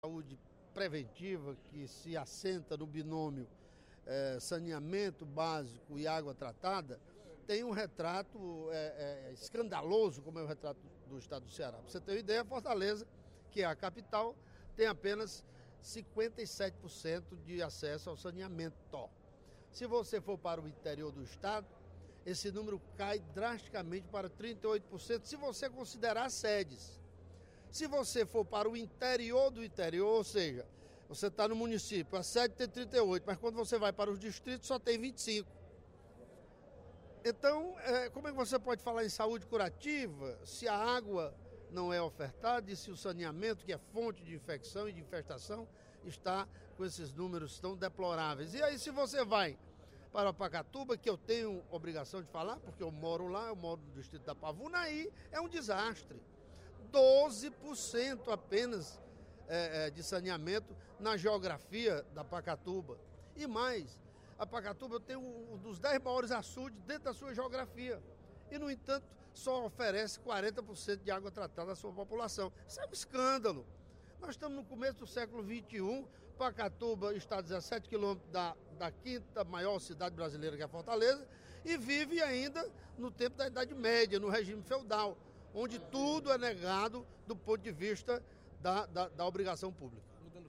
Durante o primeiro expediente da sessão plenária desta quinta-feira (28/05), o deputado Carlomano Marques (PMDB) ressaltou os problemas de saneamento básico no Ceará.